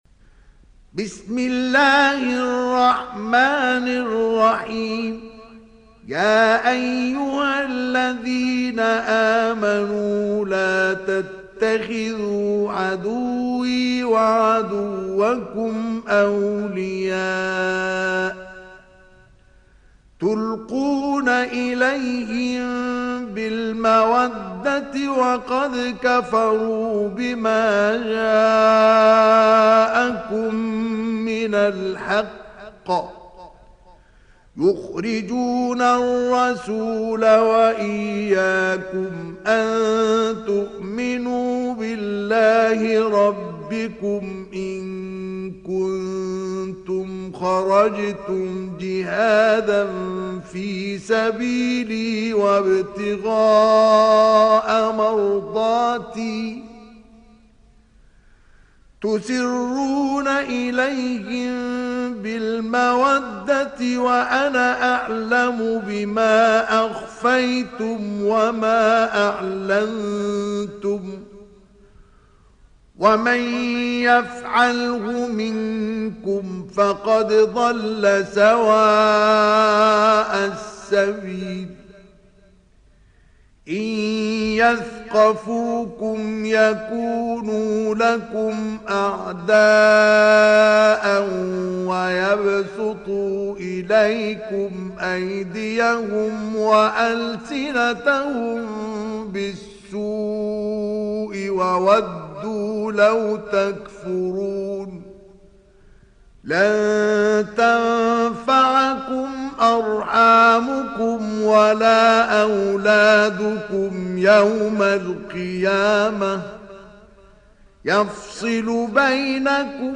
Sourate Al Mumtahinah Télécharger mp3 Mustafa Ismail Riwayat Hafs an Assim, Téléchargez le Coran et écoutez les liens directs complets mp3